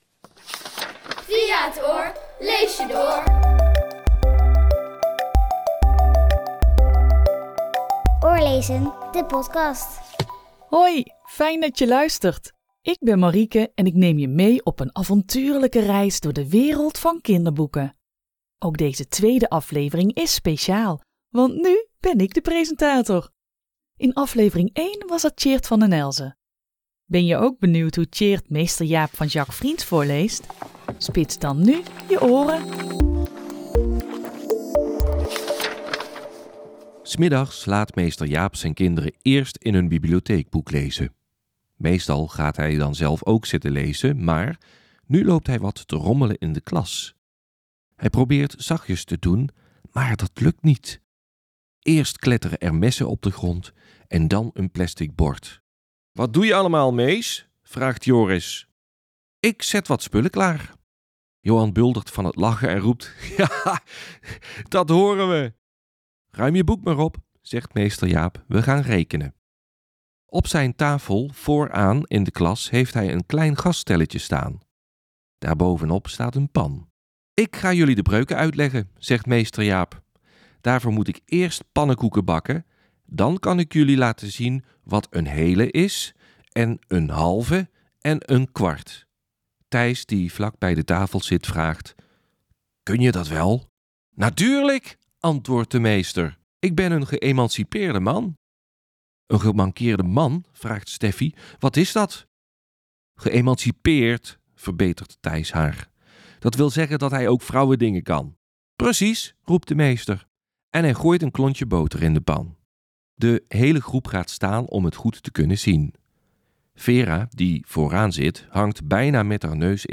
In aflevering 2 hoor je "Meester Jaap" van Jacques Vriens voorgelezen